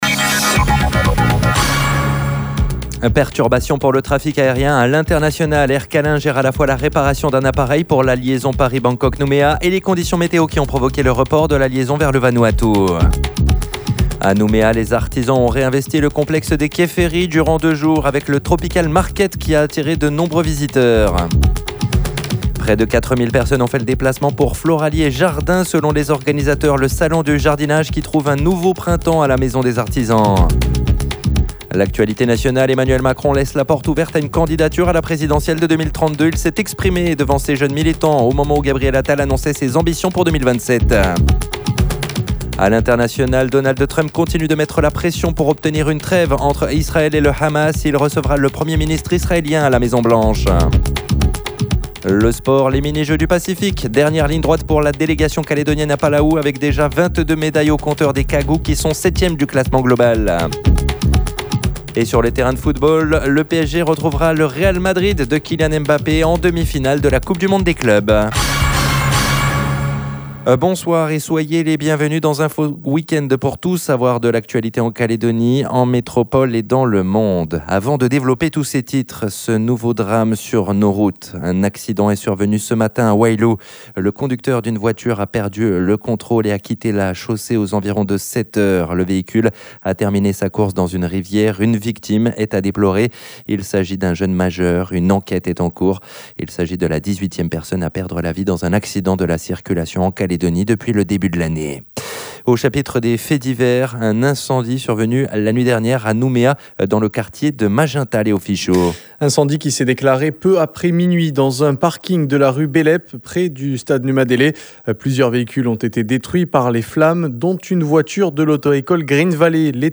JOURNAL : INFO WEEK-END DIMANCHE SOIR 06/07/25